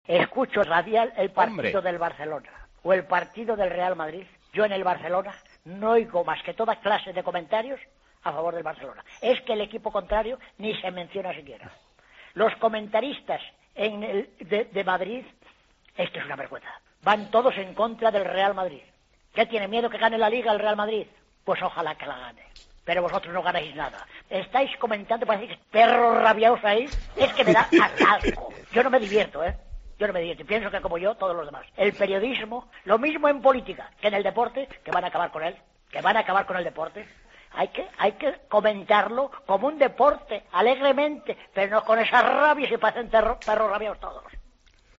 Oyente enfurecido: "Parecéis perros rabiados"